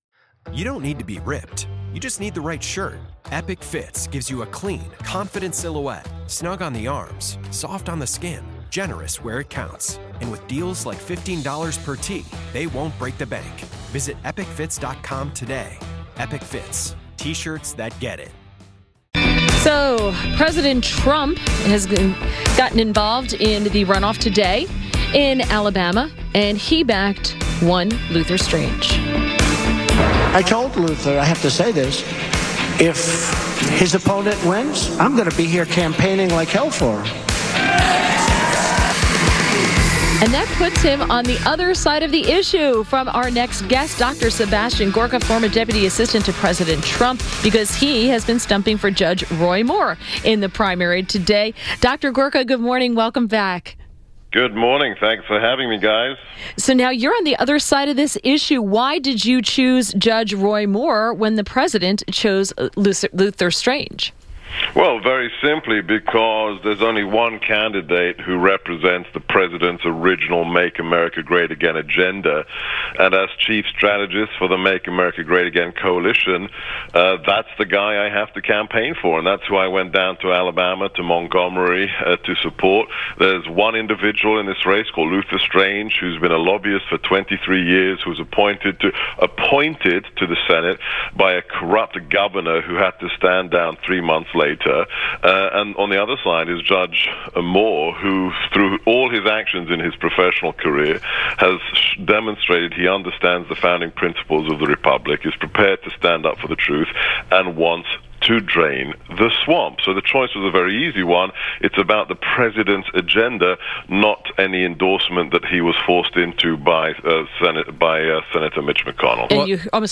INTERVIEW -- DR. SEBASTIAN GORKA - former Deputy Assistant to President Trump